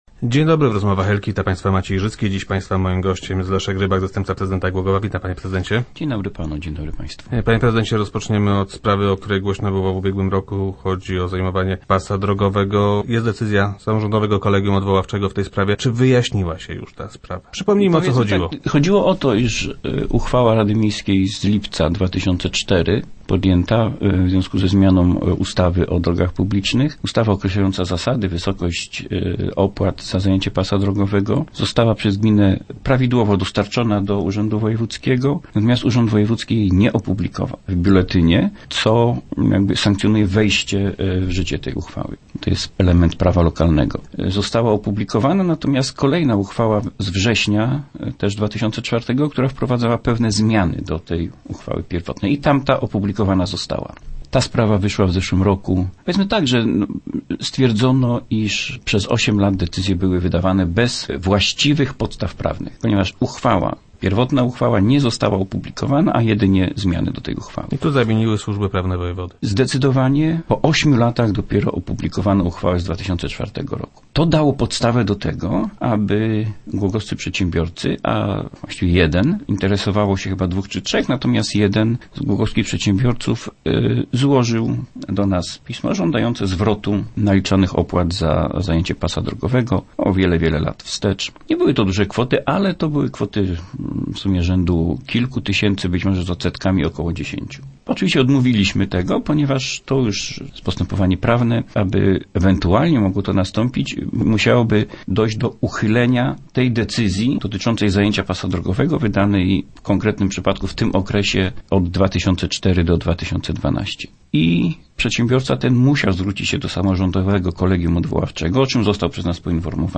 0101_rybak_do_rozmow.jpgSamorządowe Kolegium Odwoławcze orzekło, że mimo braku publikacji uchwały rady miasta o naliczaniu opłat za zajęcie pasa drogowego, wydawane przez gminę decyzje są ważne. - Spodziewaliśmy się takiego rozstrzygnięcia – twierdzi Leszek Rybak, zastępca prezydenta Głogowa, który był gościem Rozmów Elki.